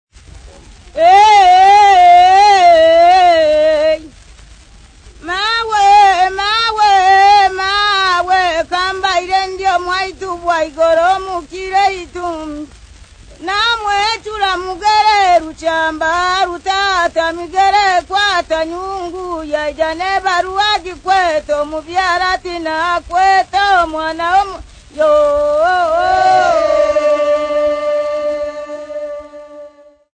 Dance music
Africa Tanzania Bukoba f-sa
field recordings
Unaccompanied wedding song